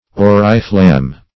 Auriflamme \Au"ri*flamme\, n.